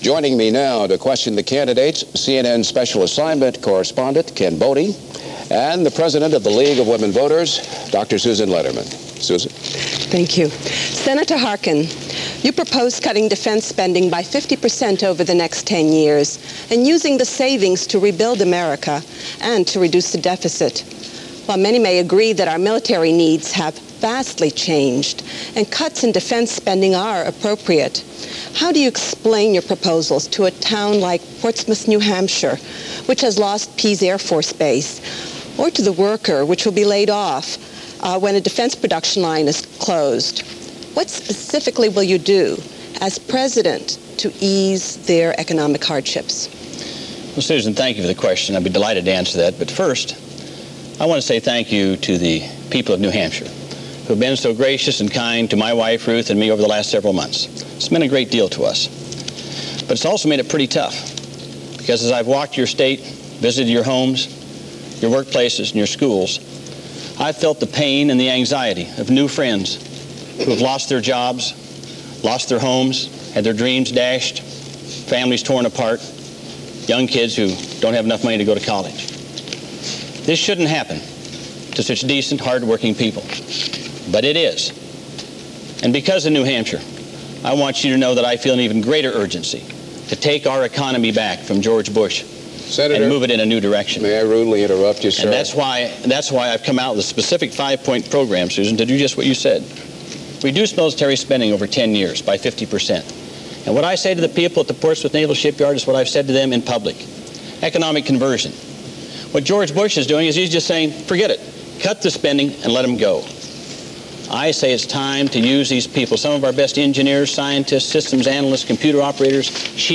Debate '92 - The New Hampshire Democratic Primary Debate - 1992 - Broadcast live on February 16, 1992 - Past Daily Reference Room.
New Hampshire and an election year; one of the few times during the year that all eyes are trained on one place at one time; St. Anselm College in Manchester.
Democratic-Candidates-Debate-1992.mp3